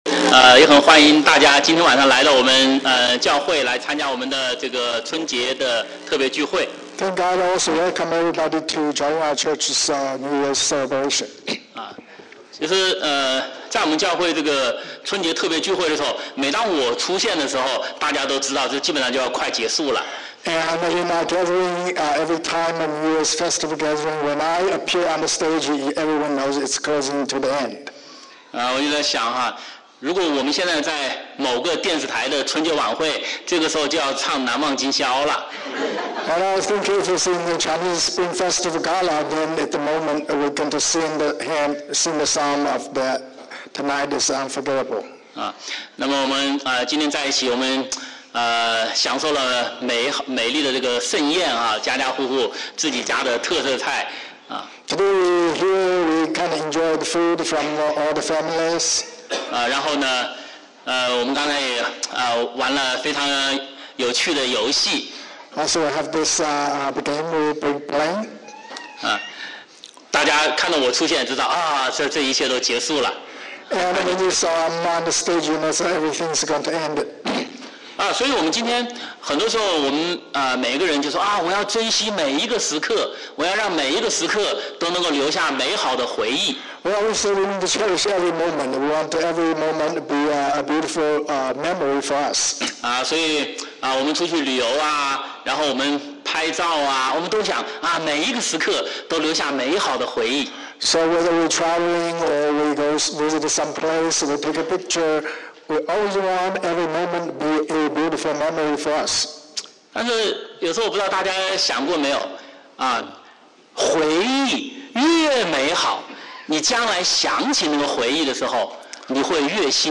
2025 Spring Festival Special Gathering 2025 春节特会短讲
Sermons